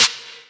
DDK1 SNARE 11.wav